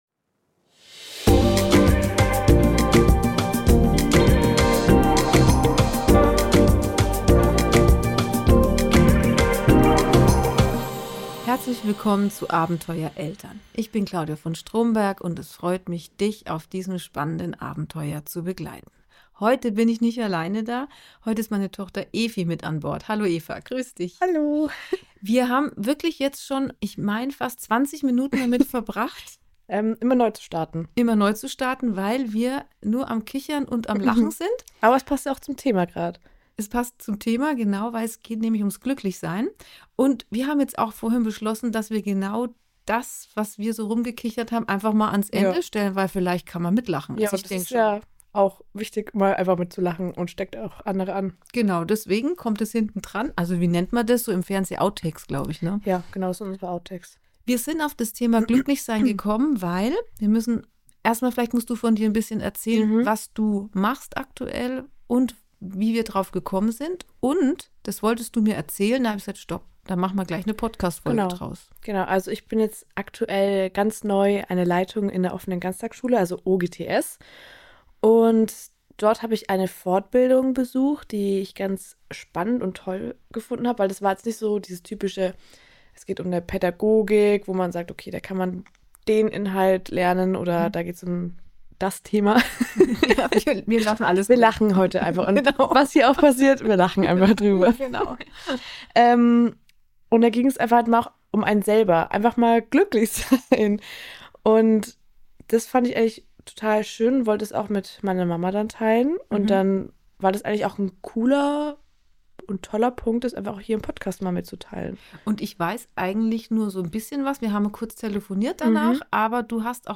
Und weil wir beide offenbar nicht geschaffen sind für „ernst bleiben“, gab es beim Aufnehmen so viele Lachanfälle, und Versprecher, dass wir euch am Ende sogar ein paar Outtakes mitgeben mussten.